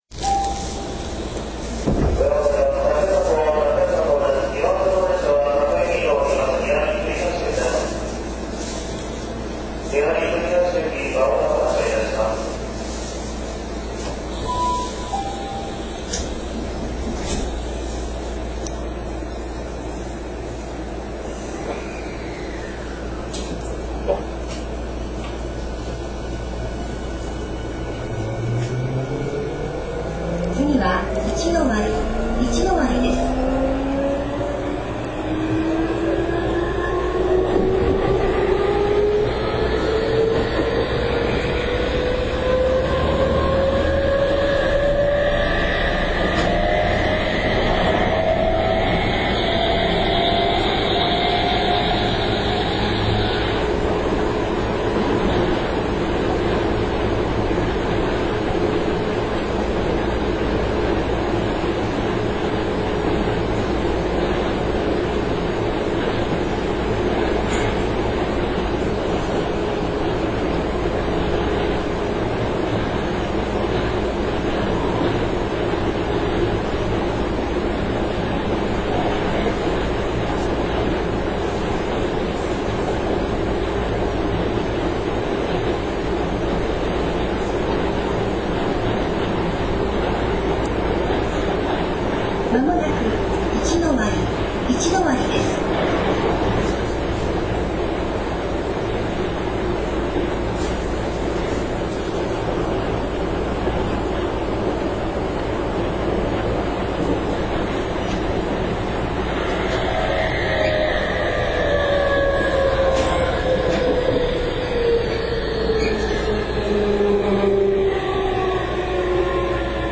Motor Sound